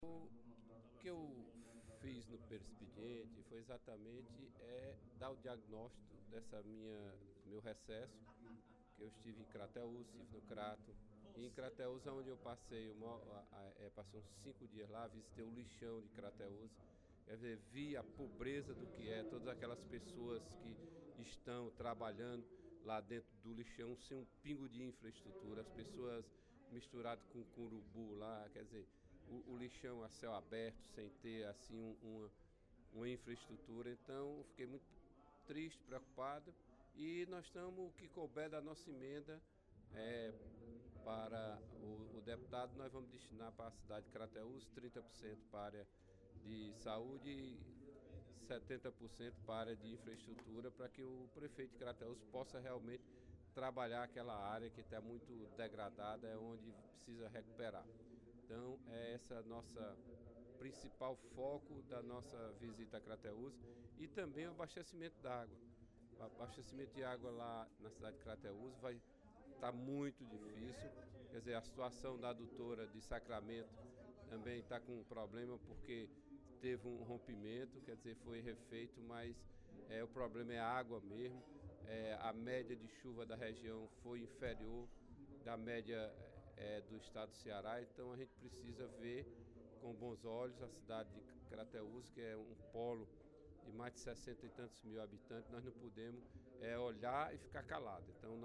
Em pronunciamento no primeiro expediente da sessão plenária desta quarta-feira (05/08), o deputado Walter Cavalcante (PMDB) demonstrou preocupação com o município de Crateús, especialmente em relação ao abastecimento de água e saneamento básico da cidade.